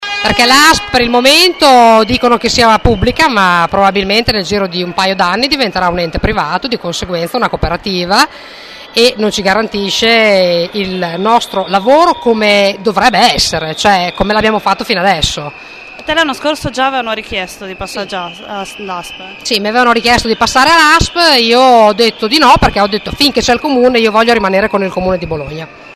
C’è anche una maestra che lavora da precaria da 16 anni.
maestra-noasp.mp3